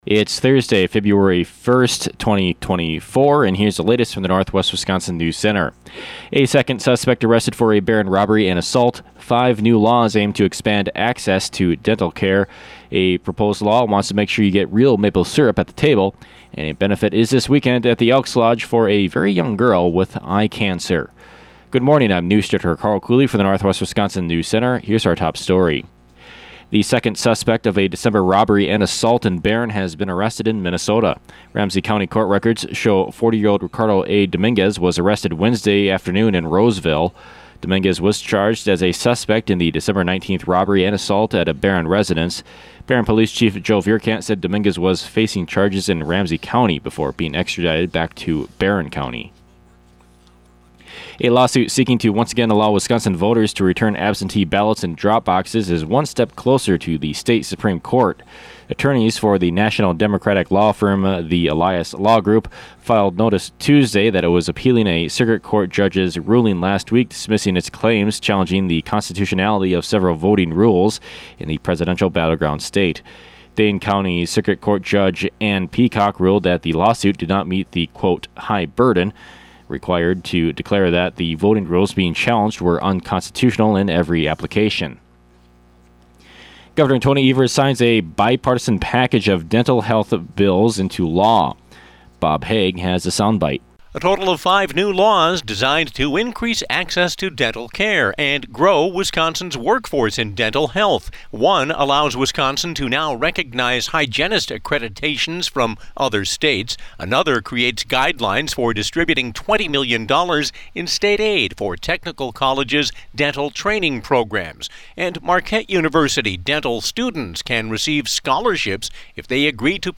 AM NEWSCAST – Thursday, Feb. 1, 2024